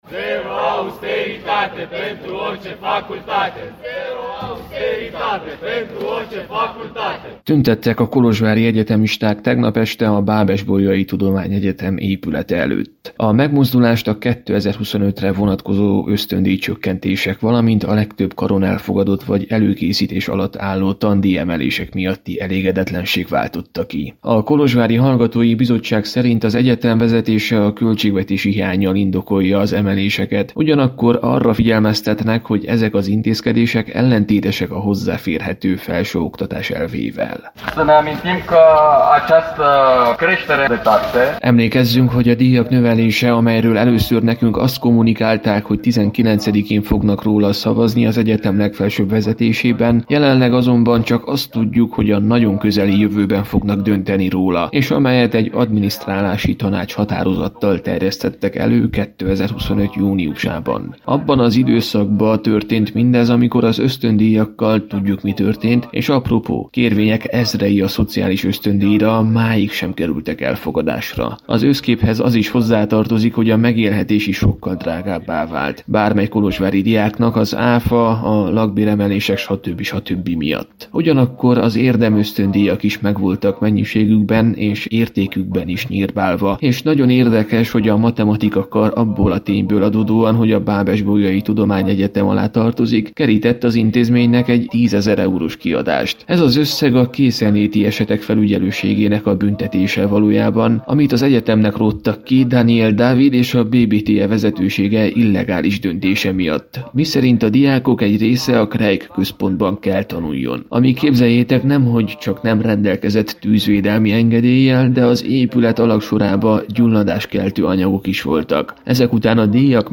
„Zajlanak a tárgyalások a középiskolások képviseletével egy általános sztrájk kirobbantása reményében” – tájékoztatta a jelenlévőket a Kolozsvári Hallgatói Bizottság tagja a tegnap esti kolozsvári tüntetésen.